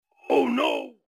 Oh no Мужской быстрый